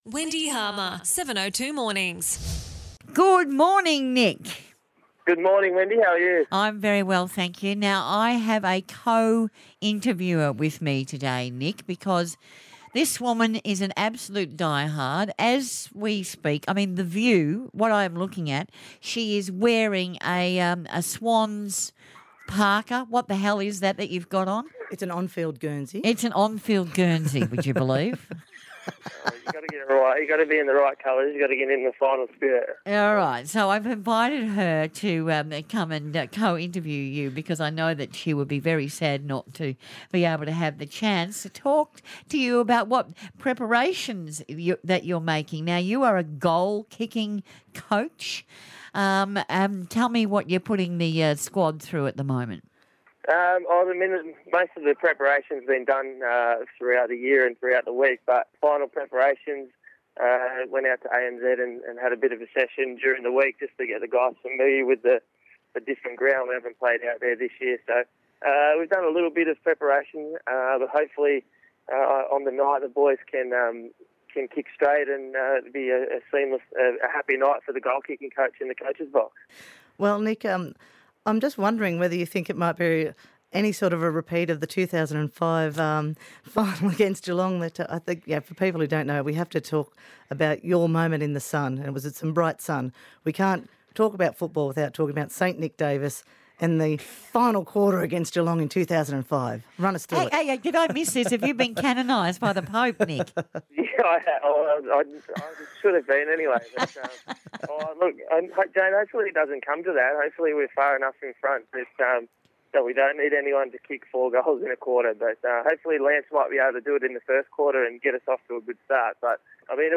2005 premiership player Nick Davis speaks with Wendy Harmer on ABC Radio.